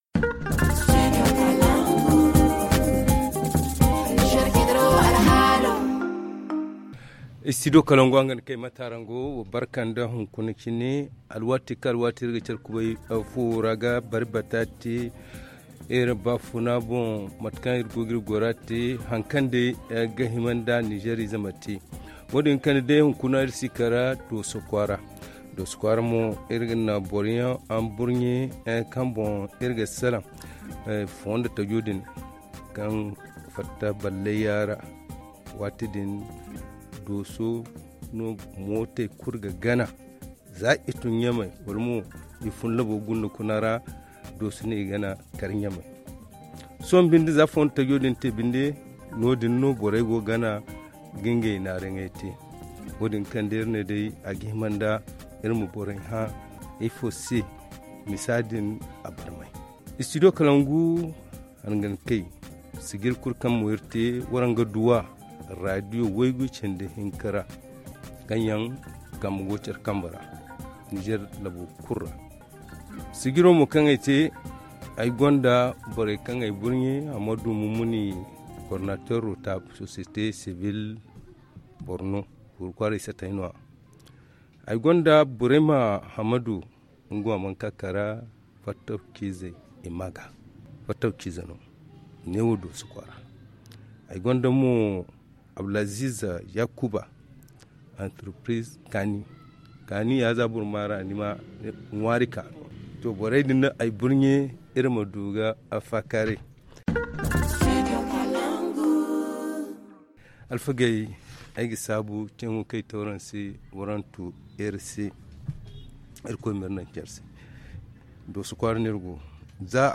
ZA Le forum en zarma Télécharger le forum ici.